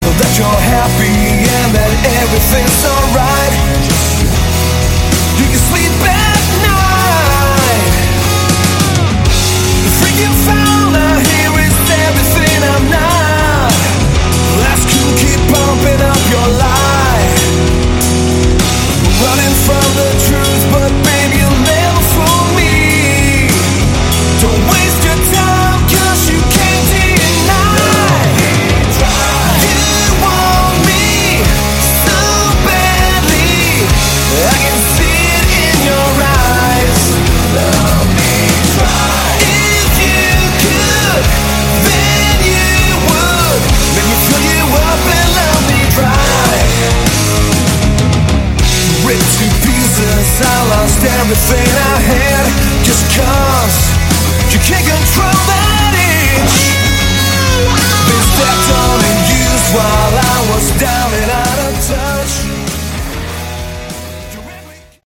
Category: Hard Rock
drums
bass
guitars
vocals
keyboards